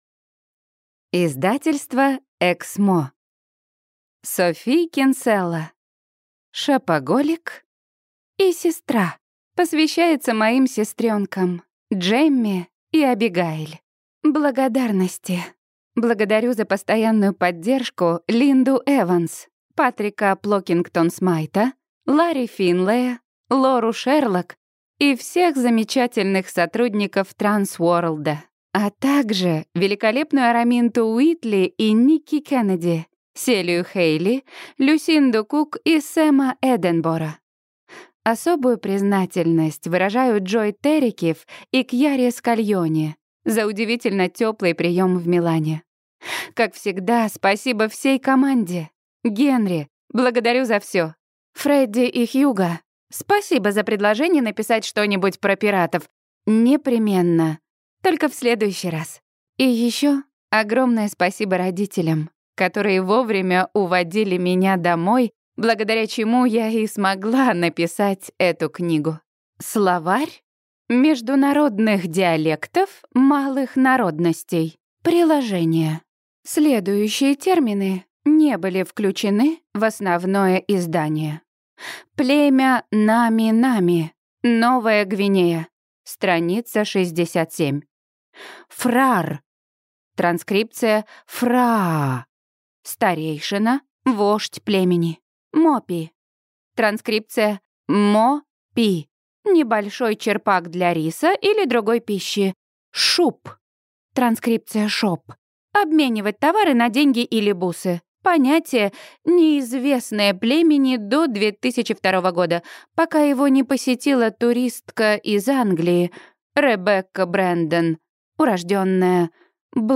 Аудиокнига Шопоголик и сестра | Библиотека аудиокниг